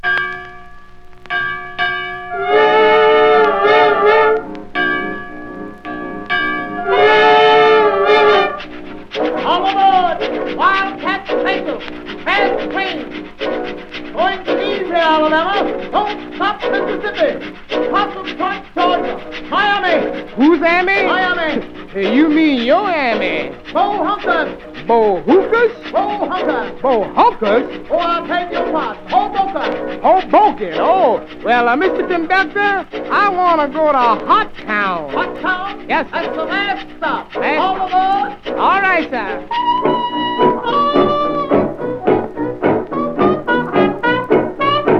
音の抜き差し、機知に富んだアレンジが素晴らしい。テンション高め、興奮必至の演奏は圧巻です。
Jazz　Canada　12inchレコード　33rpm　Mono